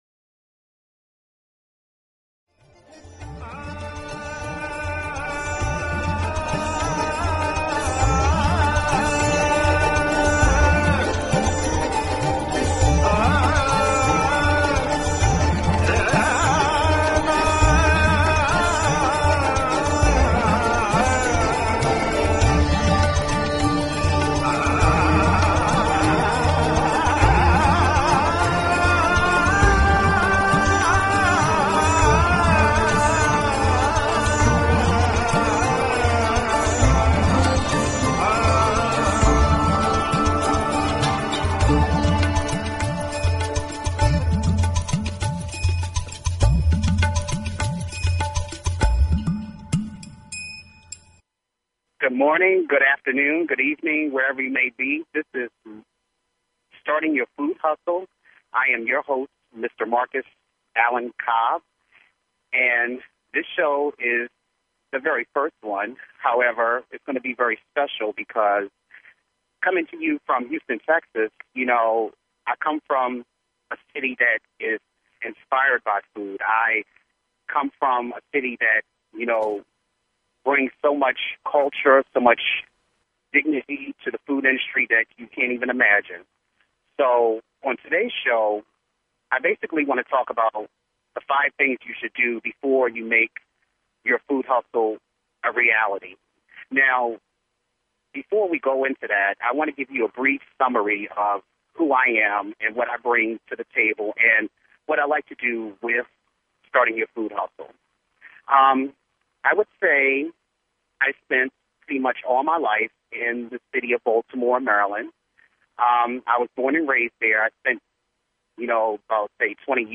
Talk Show Episode, Audio Podcast, Starting_Your_Food_Hustle and Courtesy of BBS Radio on , show guests , about , categorized as